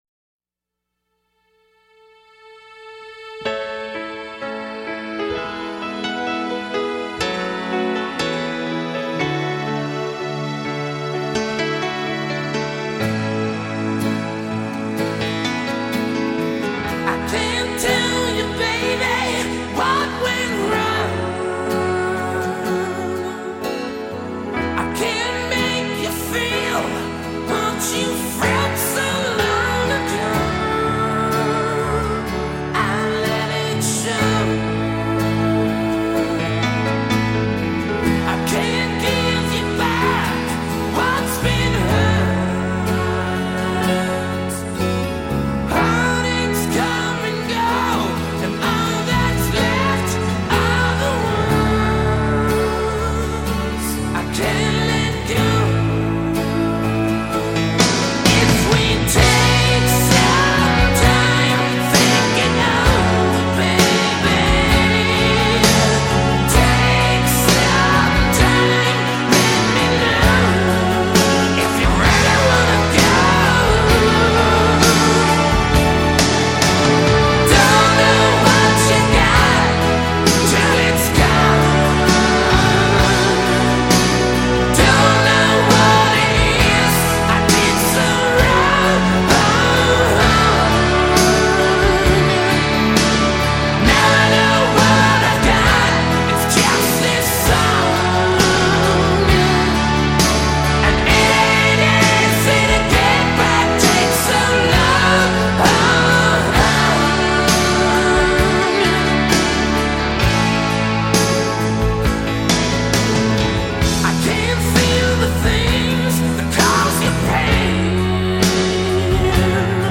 Жанр: classicmetal